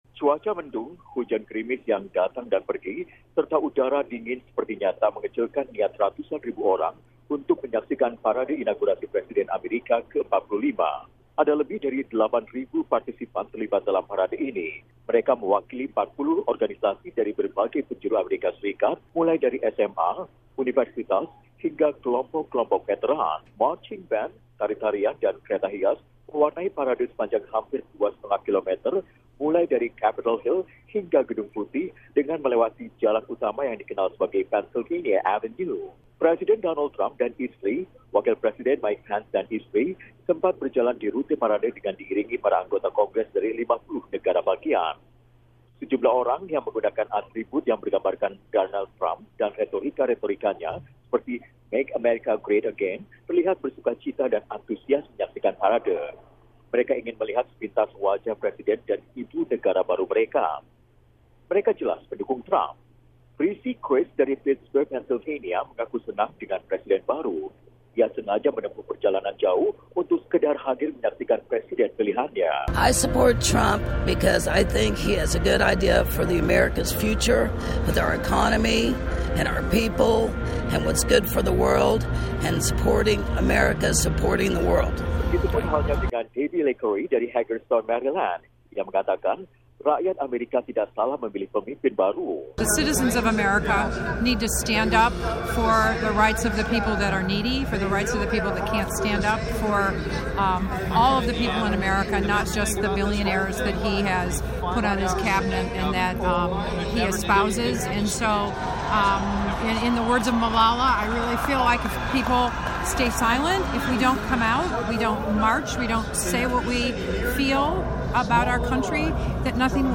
Parade dan perayaan usai pelantikan Presiden Amerika ke 45 Donald Trump berlangsung di ibukota Washington DC setelah upacara pelantikan di tangga Gedung Capitol. Reporter VOA